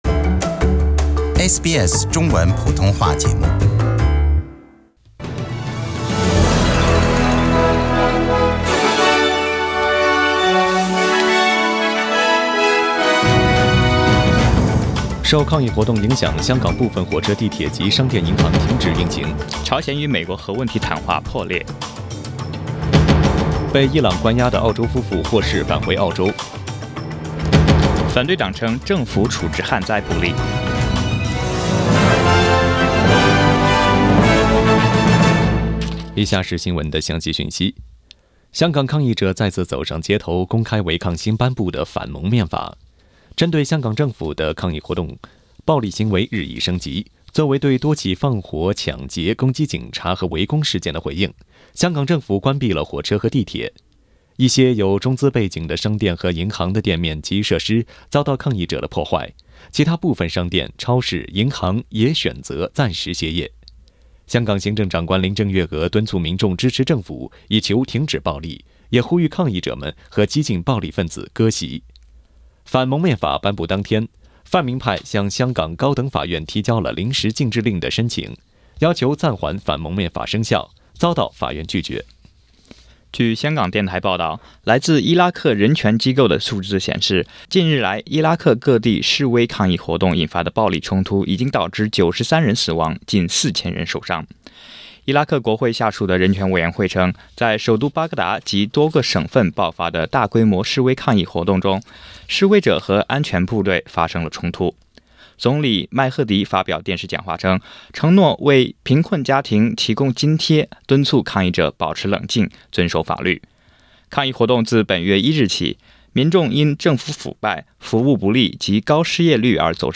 SBS 早新闻（10月6日）